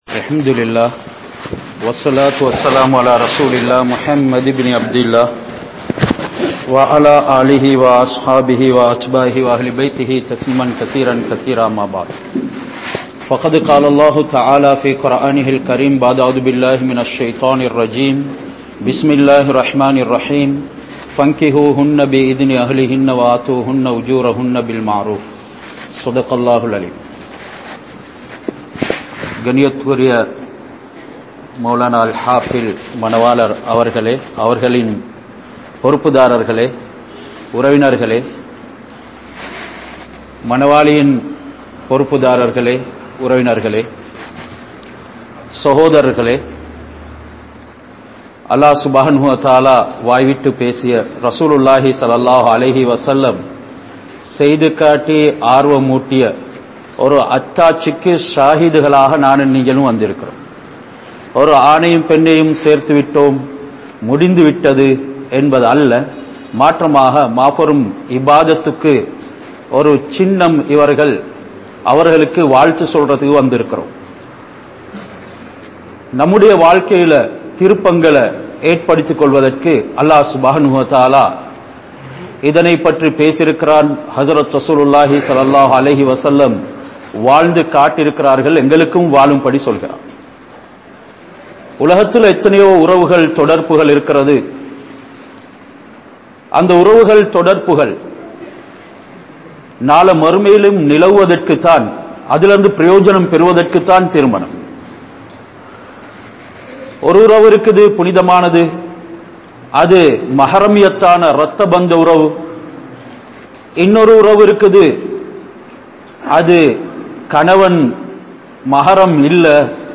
Nimmathiyai Tholaitha Kudumpangal (நிம்மதியை தொலைத்த குடும்பங்கள்) | Audio Bayans | All Ceylon Muslim Youth Community | Addalaichenai
Andiya Kadawatha Jumua Masjidh